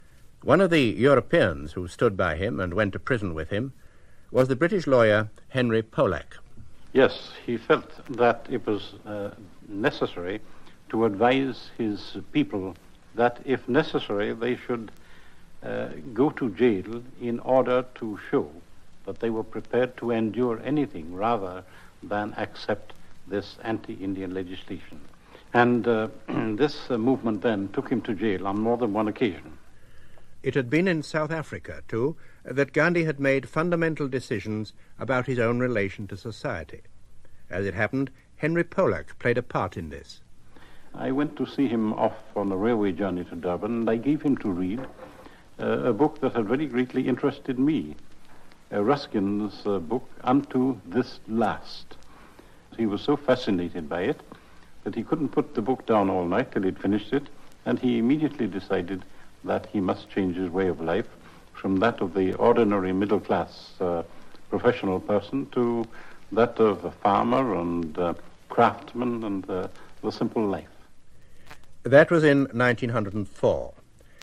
Original Voice of Henry S. Polak on Gandhi, Ruskin's Unto This Last and Phoenix Settlement
Original Voice of Henry S Polak, an excerpt from "Gandhi, Man on Trial" (BBC, 1972)